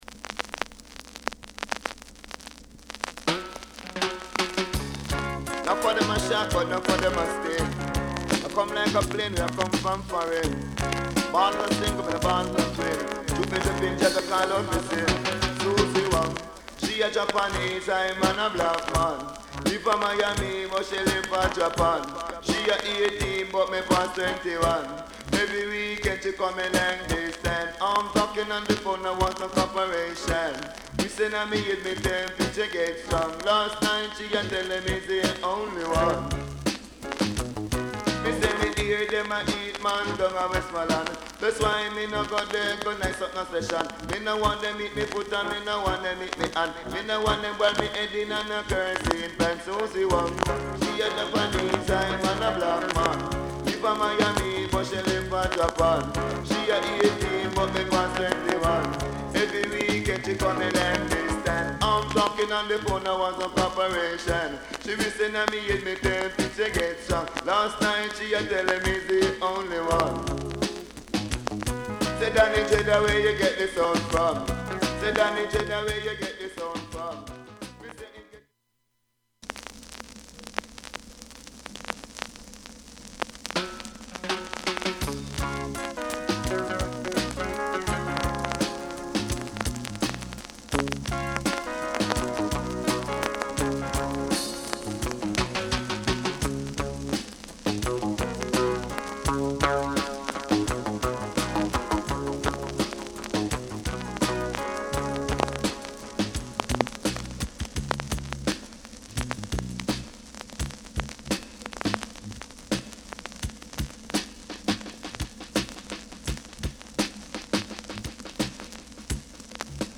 Genre: Dancehall